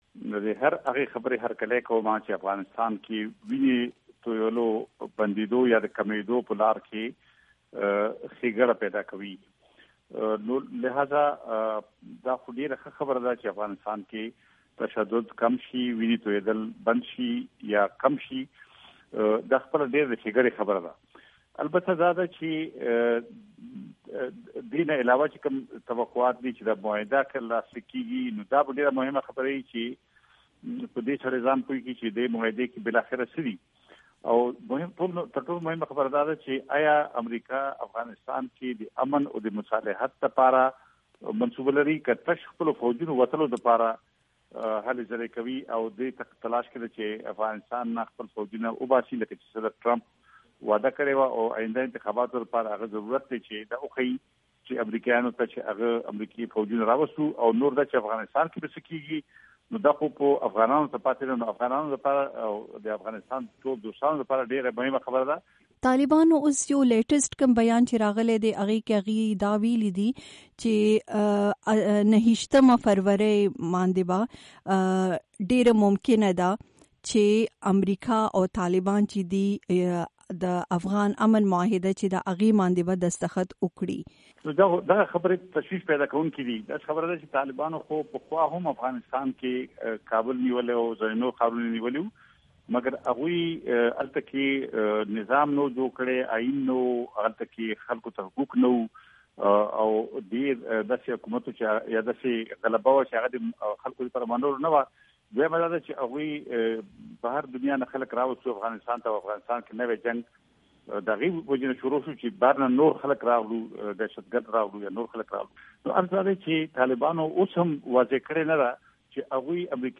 افراسیاب خټک د سیاسي چارو کارپوه
د تاوتریخوالي کمیدو په اړه له سیاسي کارپوه افراسیاب خټک سره مرکه